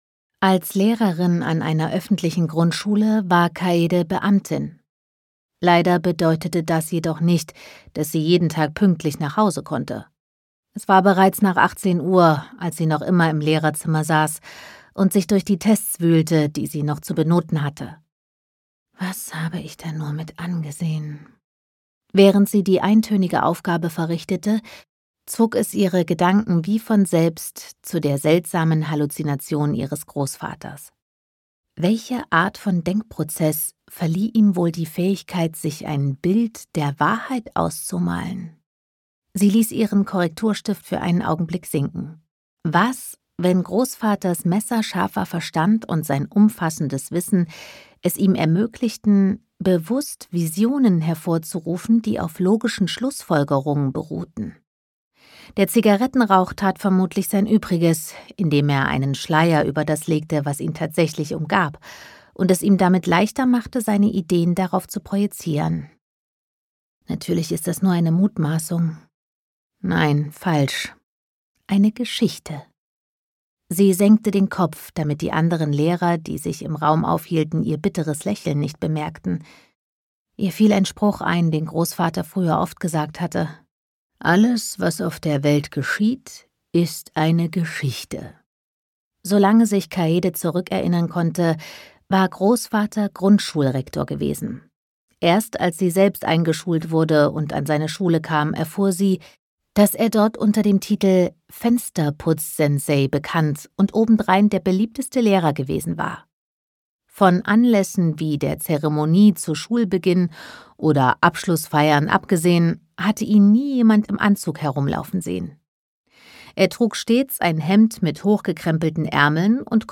Ein berührendes Hörbuch über die Kraft von Büchern und Geschichten – spannend, charmant und voller Herz.
Gekürzt Autorisierte, d.h. von Autor:innen und / oder Verlagen freigegebene, bearbeitete Fassung.